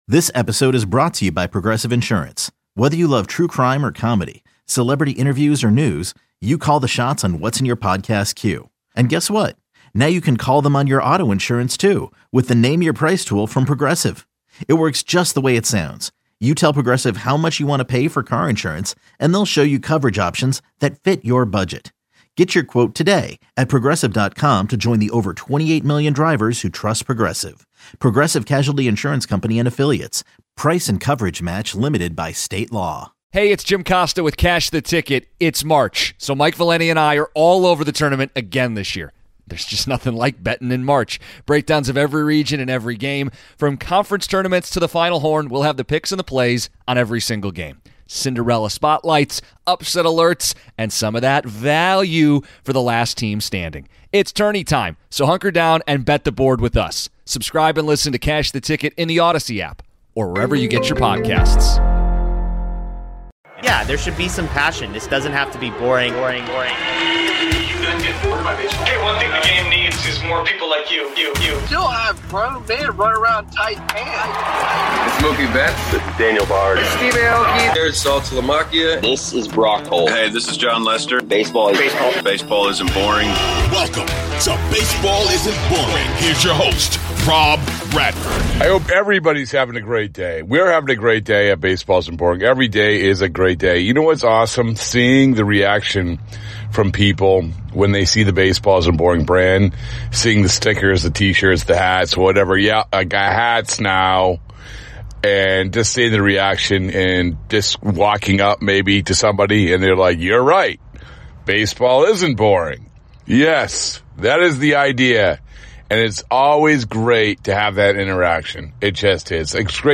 Red Sox-related audio from WEEI shows and podcasts, including postgame interviews.